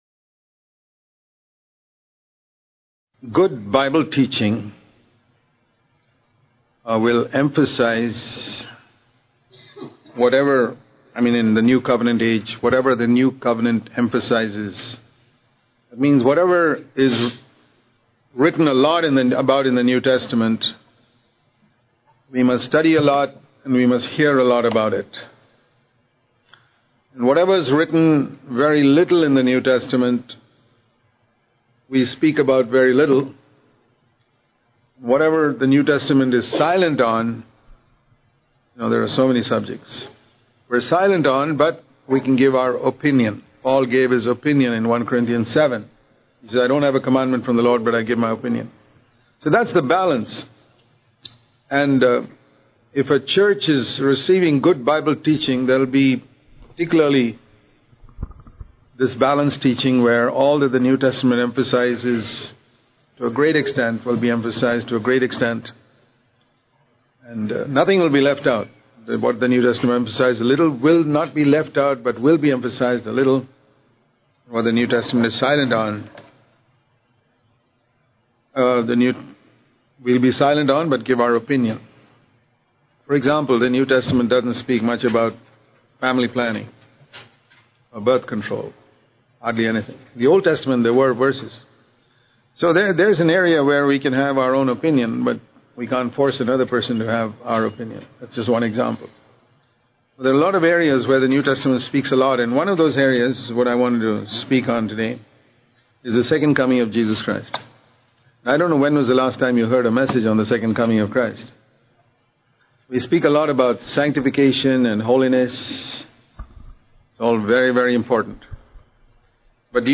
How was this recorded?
at NCCF, California, USA